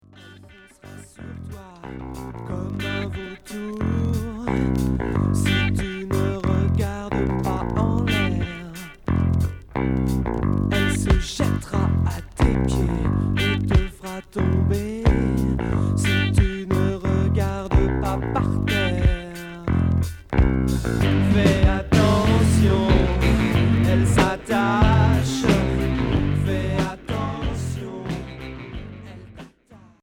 Rock new wave Deuxième 45t retour à l'accueil